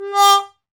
Index of /90_sSampleCDs/Roland L-CDX-03 Disk 2/BRS_Trombone/BRS_TromboneMute